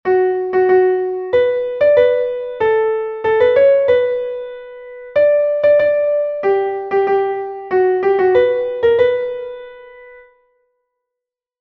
Entoación a capella
Melodía 2/4 en Si m